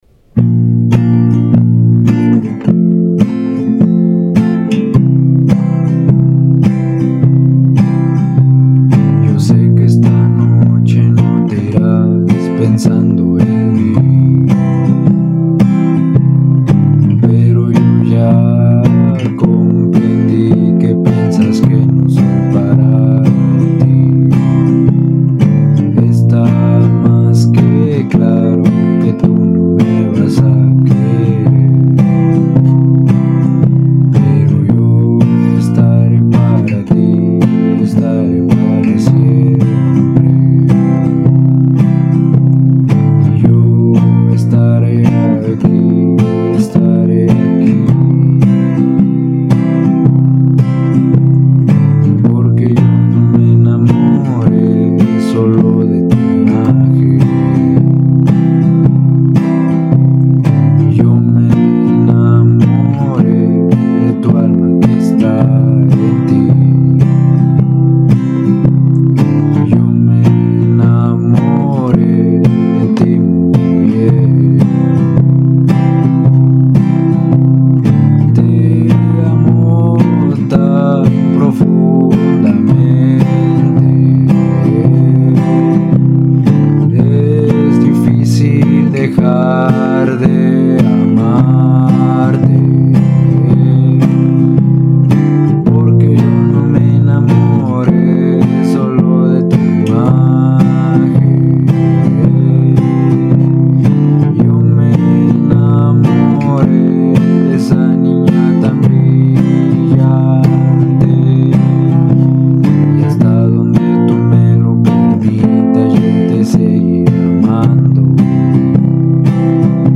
chequen está versión acústica de mi canción